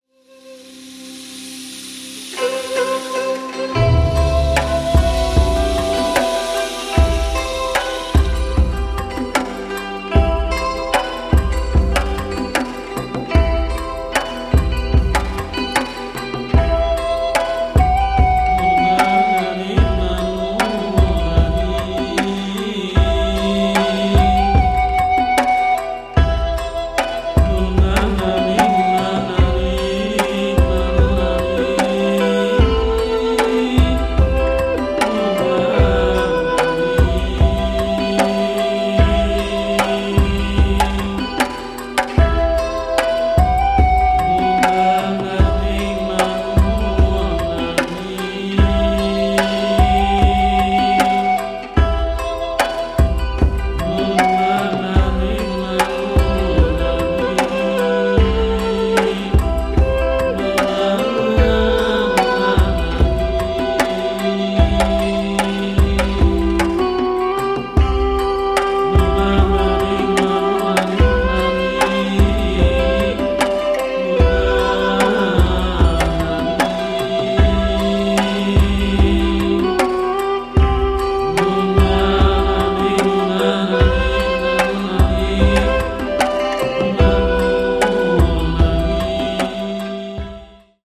Style:World / Electronic / Other